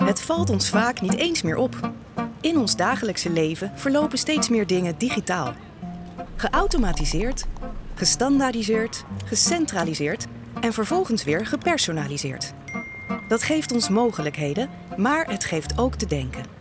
Vídeos Explicativos
Sou uma locutora profissional holandesa com uma voz clara, calorosa e fresca.